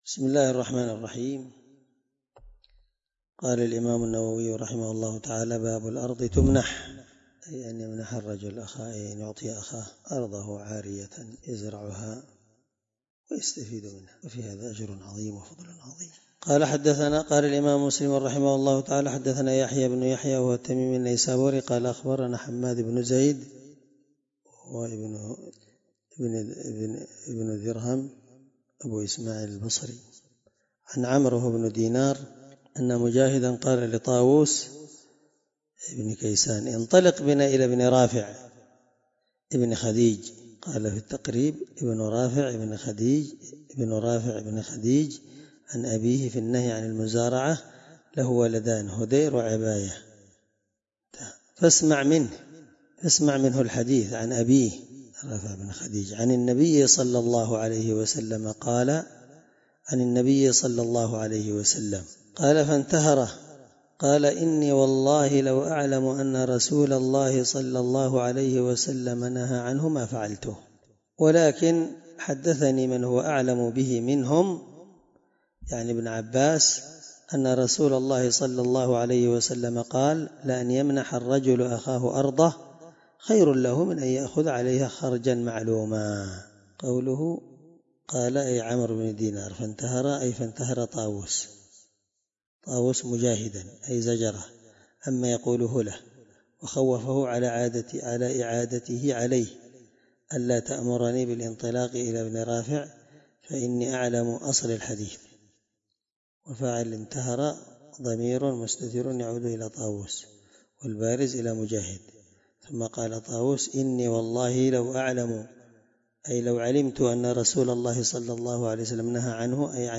الدرس25من شرح كتاب البيوع حديث رقم(1550) من صحيح مسلم